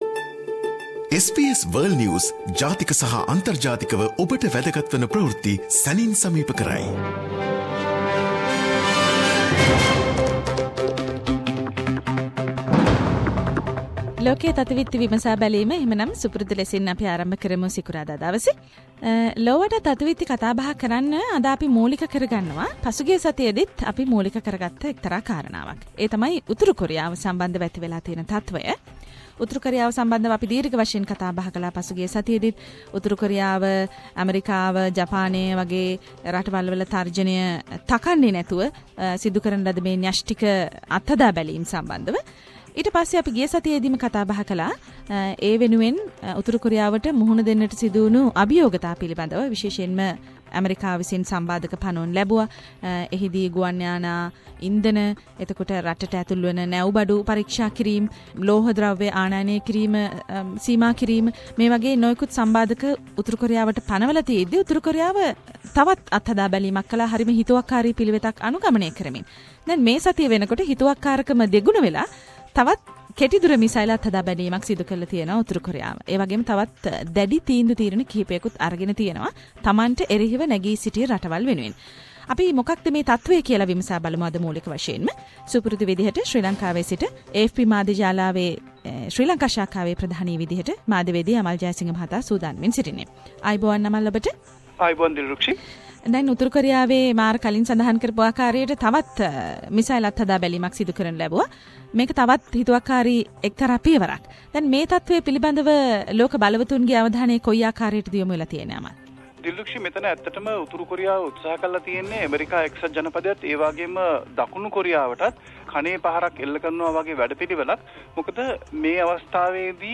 SBS Sinhala Around the World - Weekly World News highlights